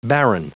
Prononciation du mot barren en anglais (fichier audio)
Prononciation du mot : barren